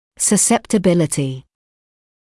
[səˌseptə’bɪlətɪ][сэˌсэптэ’билэти]чувствительность, восприимчивость; подверженность